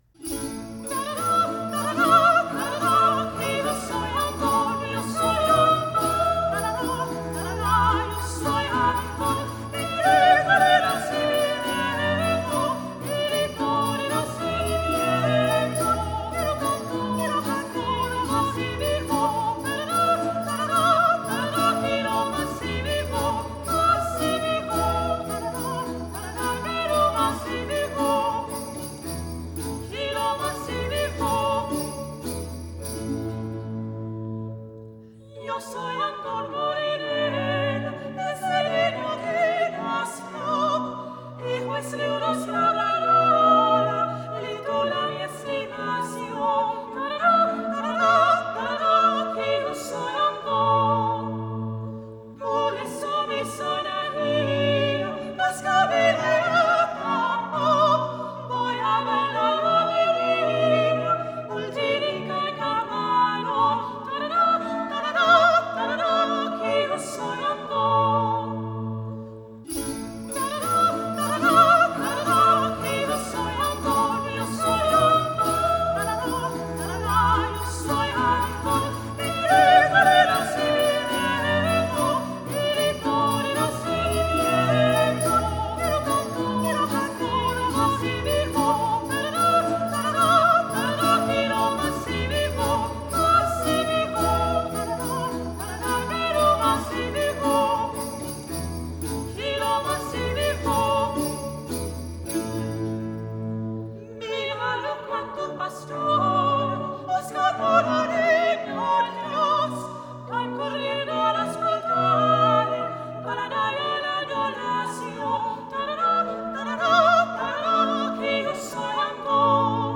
Canto
Música tradicional
Villancico negro
Orquesta